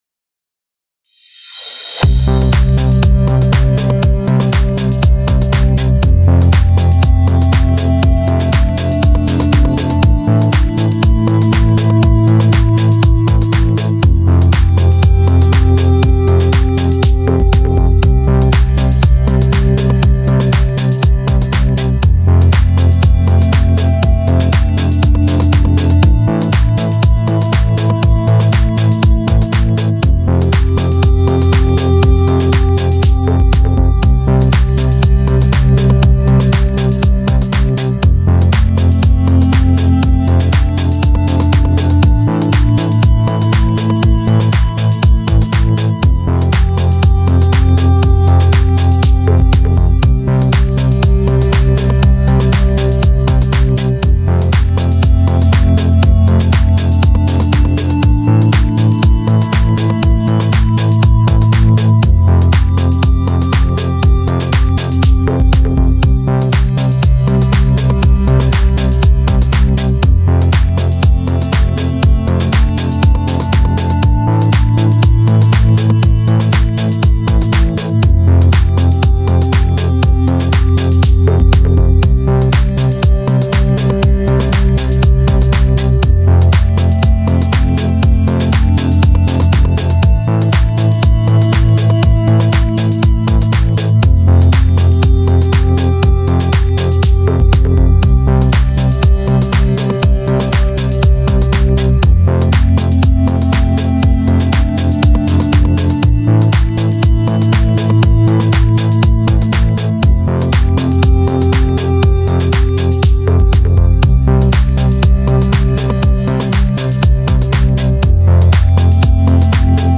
• Tags Audio, Contemporary